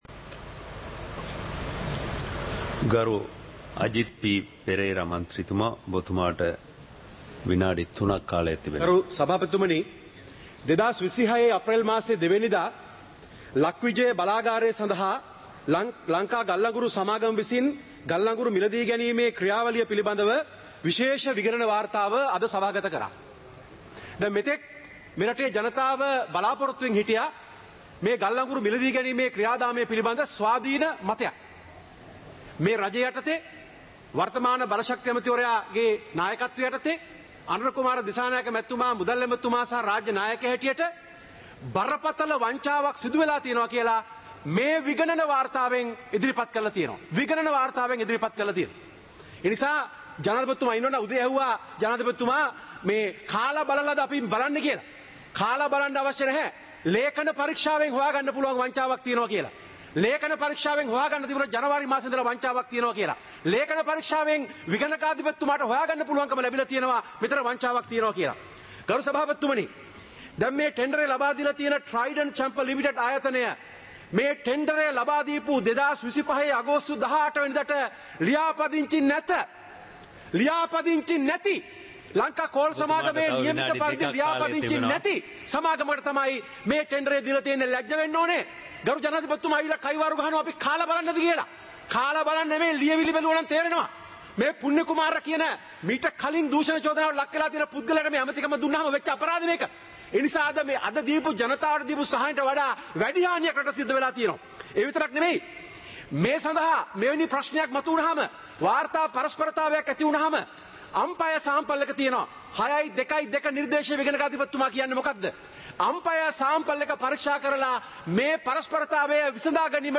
சபை நடவடிக்கைமுறை (2026-04-07)